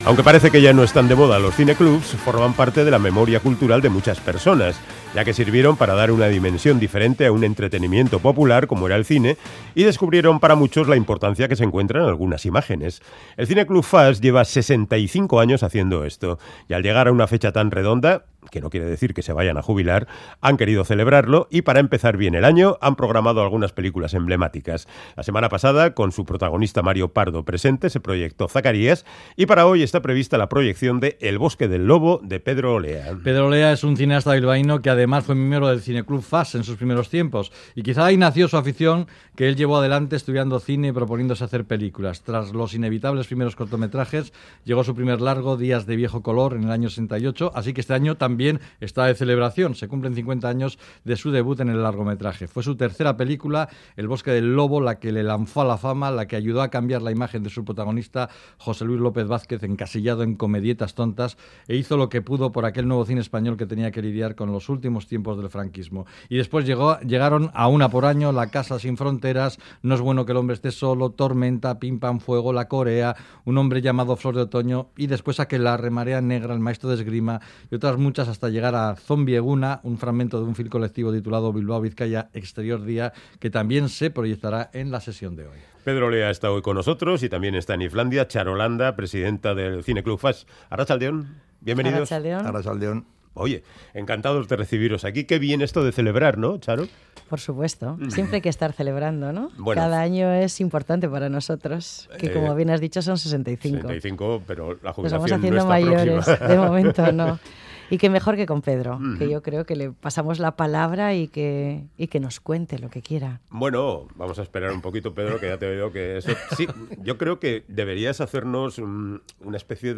Charlamos con Pedro Olea, director de cine